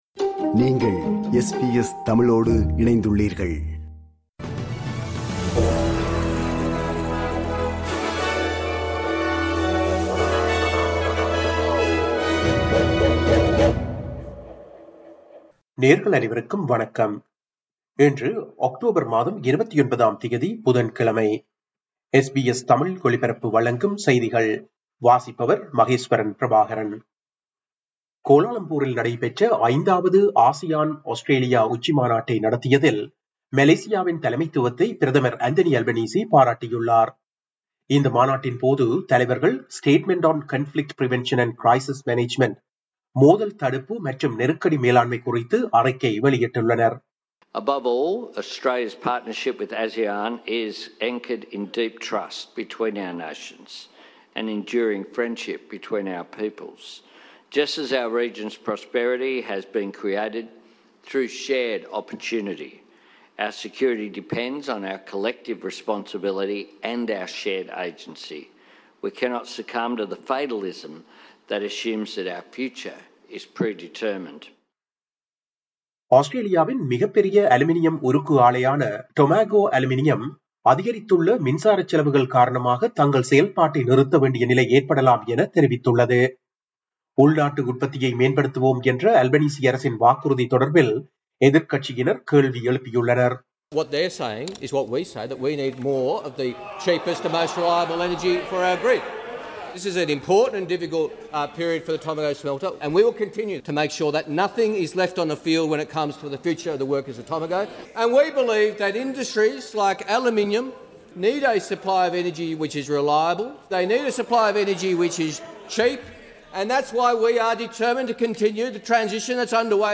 இன்றைய செய்திகள்: 29 அக்டோபர் 2025 புதன்கிழமை
SBS தமிழ் ஒலிபரப்பின் இன்றைய (புதன்கிழமை 29/10/2025) செய்திகள்.